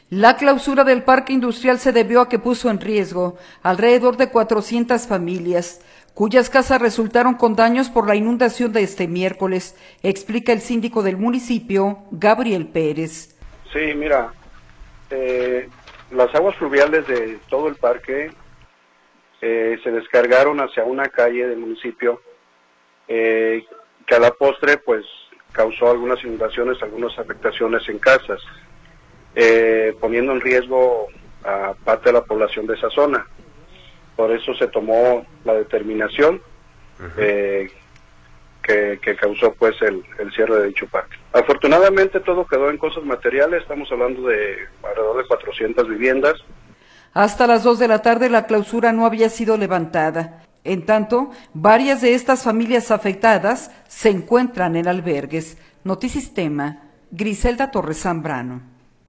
La clausura del Parque Industrial se debió a que puso en riesgo a alrededor de 400 familias, cuyas casas resultaron don daños por la inundación de este miércoles, explica el síndico del municipio, Gabriel Pérez.